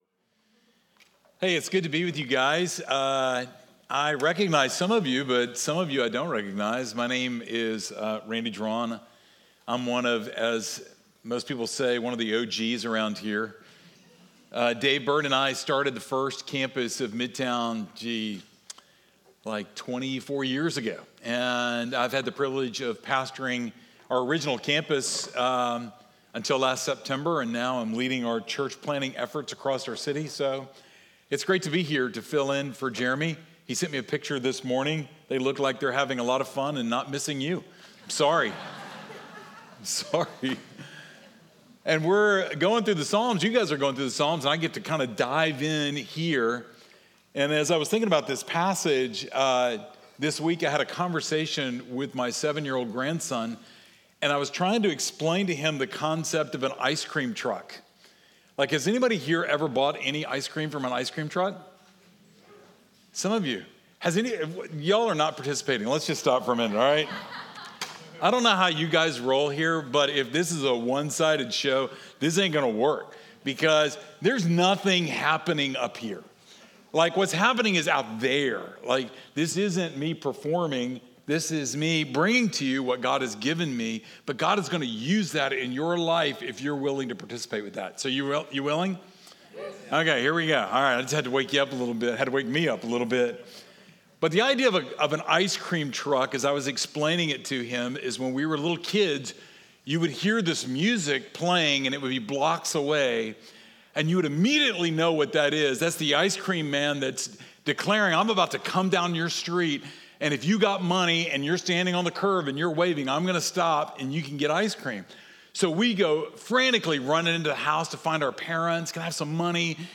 Midtown Fellowship Crieve Hall Sermons Through the Valley Jun 30 2024 | 00:33:57 1x 00:00 / 00:33:57 Subscribe Share Apple Podcasts Spotify Overcast RSS Feed Share Link Embed